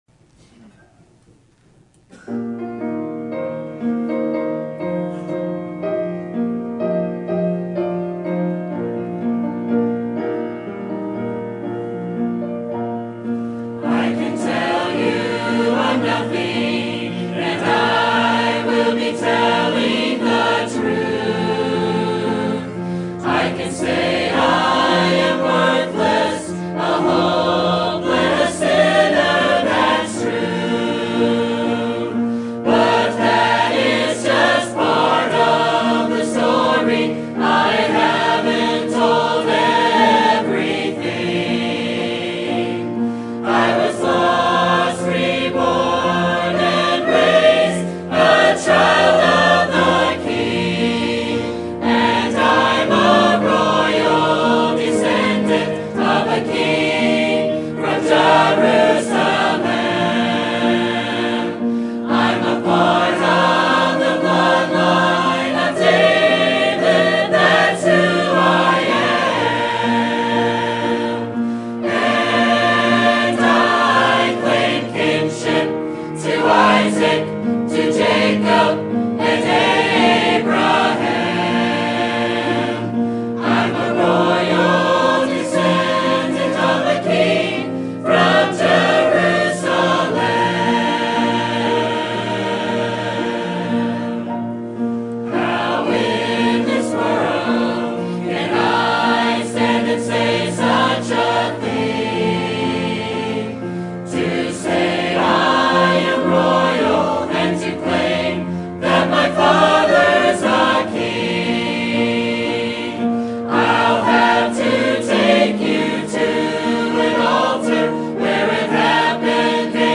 Sermon Topic: General Sermon Type: Service Sermon Audio: Sermon download: Download (33.04 MB) Sermon Tags: Genesis Simple Life God